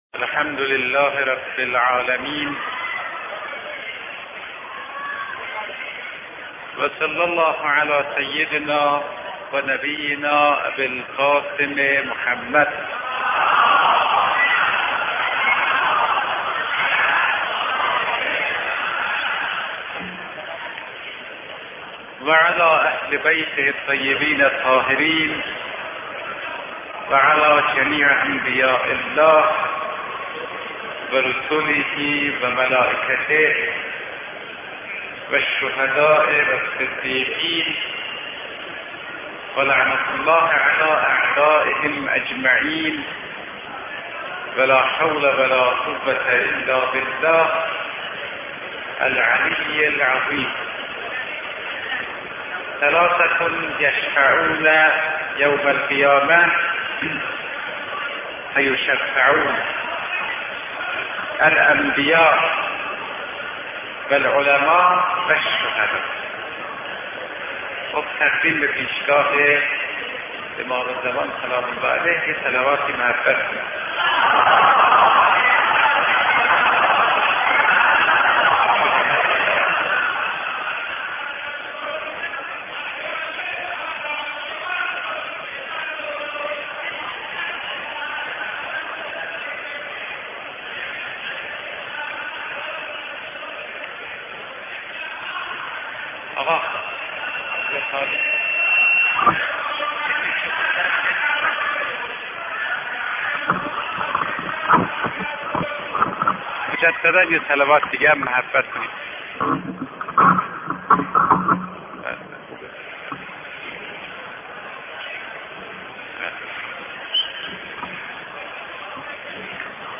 سخنران: مرحوم آیت الله فاطمی نیا
مناسبت سخنرانی: مجلس بزرگداشت مرجع عالیقدر آیت الله العظمی گلپایگانی (ره)